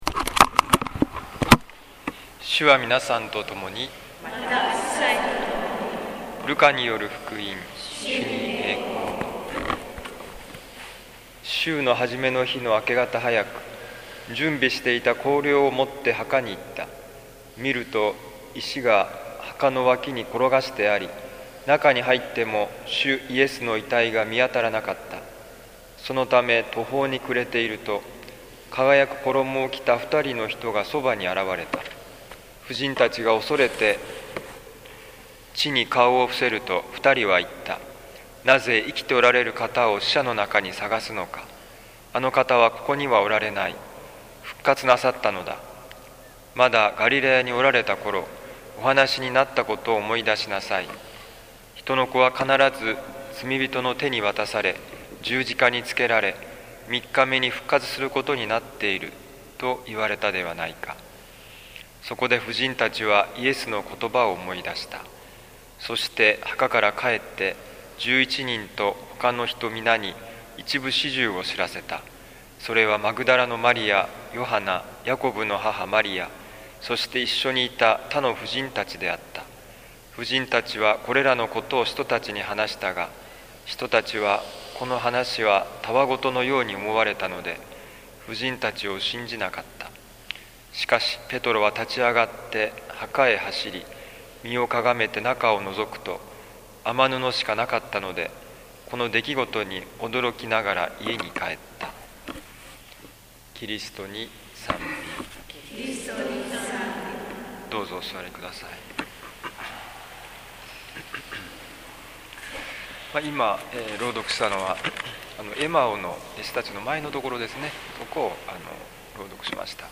ルカ福音書24章1-12節｢洗礼―人生の紅海を渡る時｣2010年4月3日復活徹夜祭のミサ